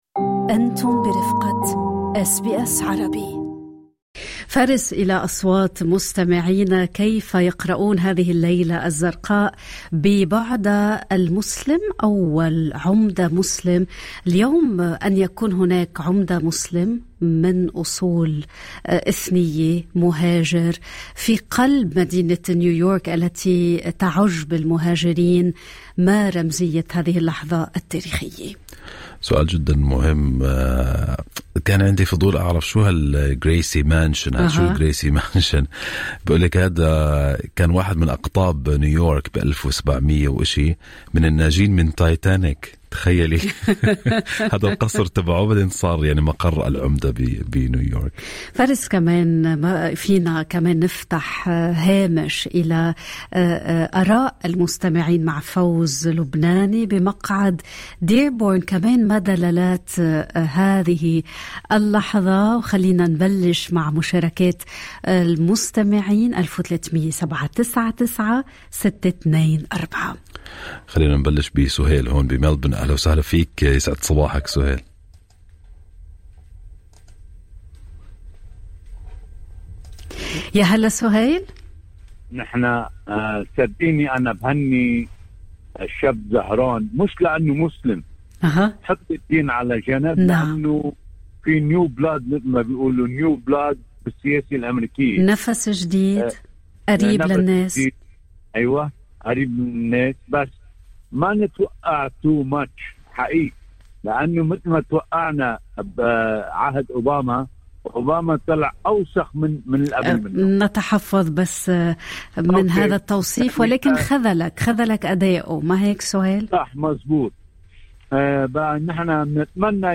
هذا ما قاله الأستراليون العرب عبر برنامج "صباح الخير أستراليا".